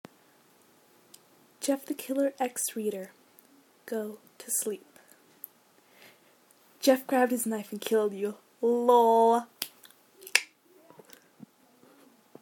Wheeze